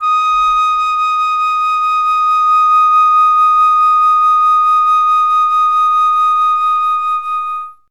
51c-flt10-D#5.wav